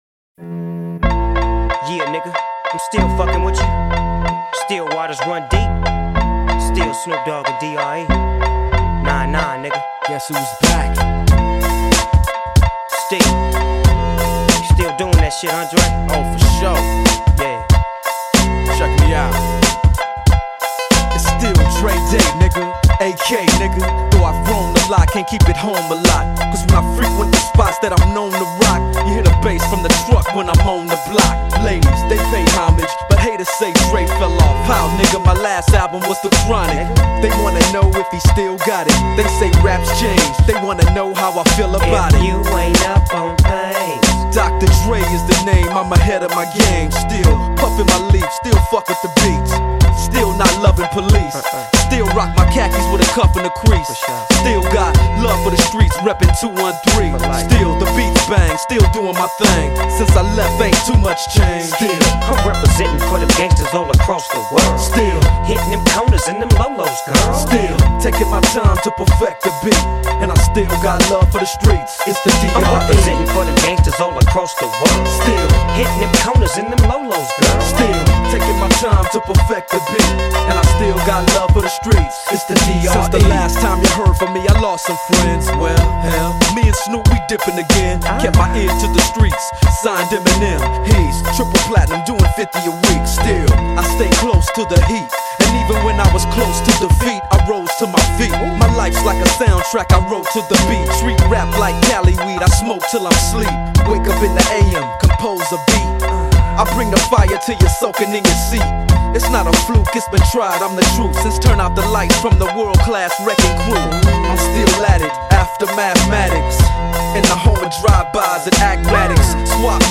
HipHop 90er